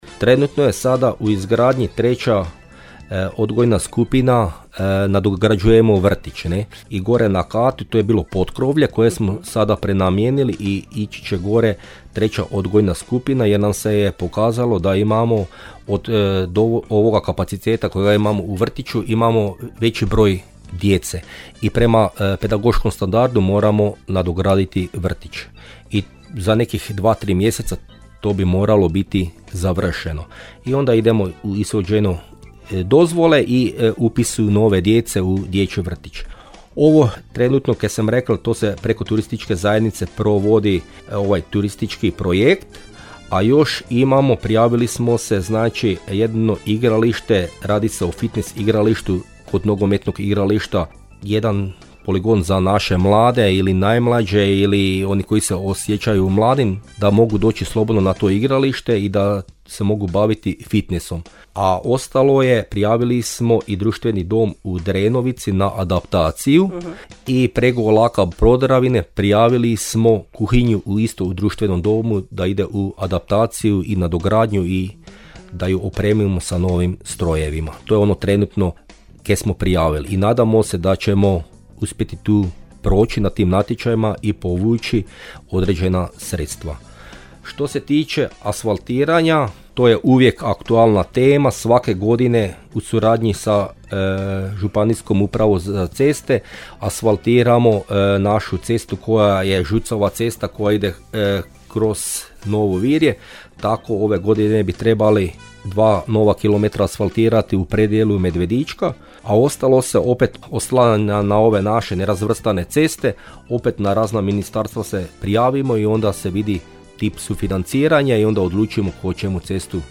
U emisiji Susjedne općine razgovarali smo sa načelnikom općine Novo Virje Mirkom Remetovićem.